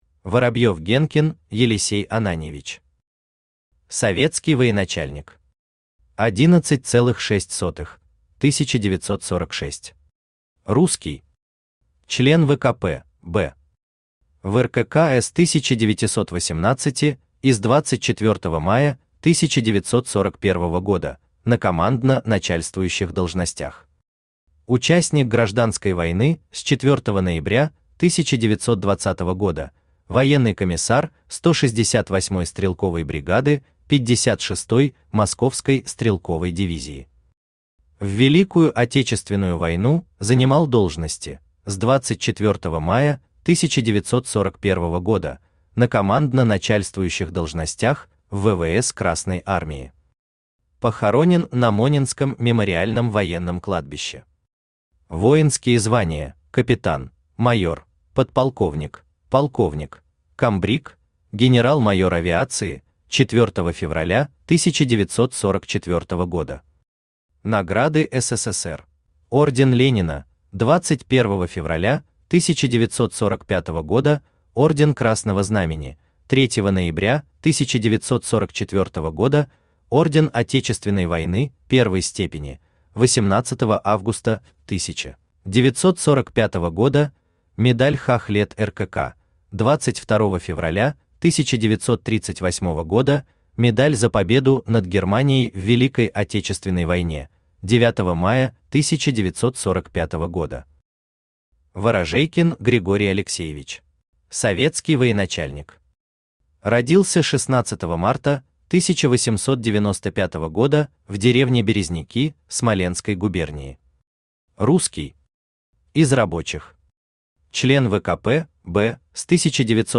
Аудиокнига Все генералы Сталина. Авиация. Том 4 | Библиотека аудиокниг
Том 4 Автор Денис Соловьев Читает аудиокнигу Авточтец ЛитРес.